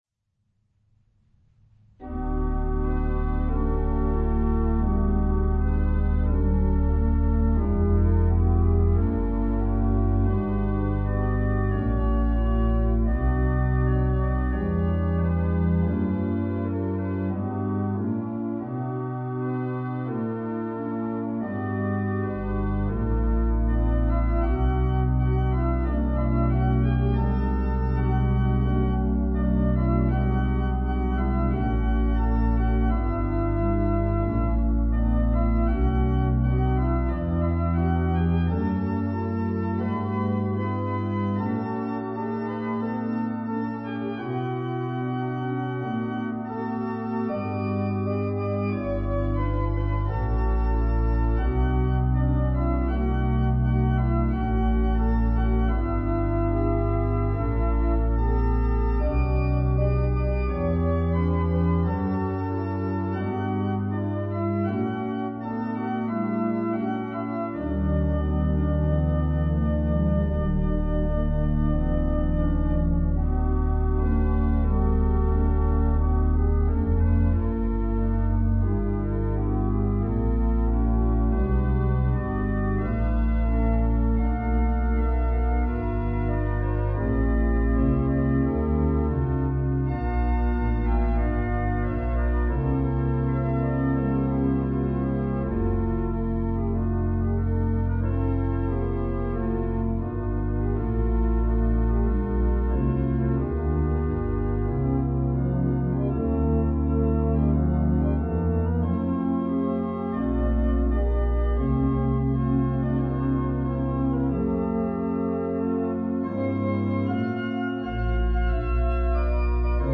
peaceful setting
Preludes